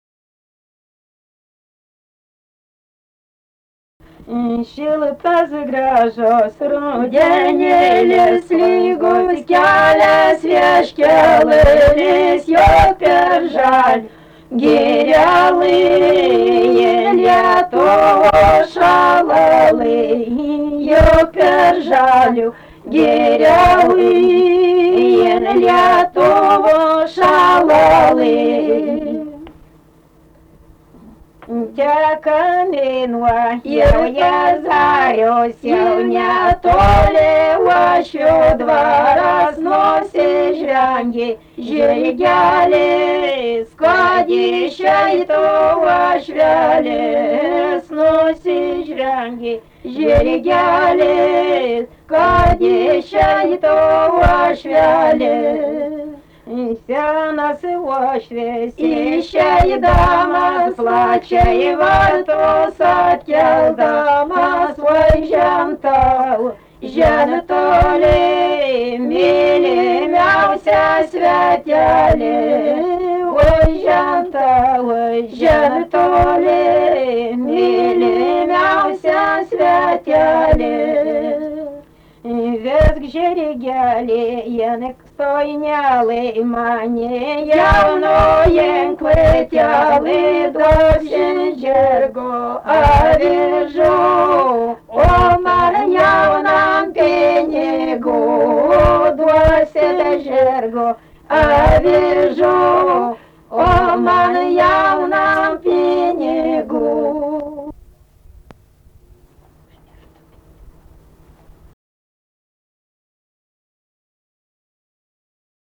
daina
Senoji Varėna
vokalinis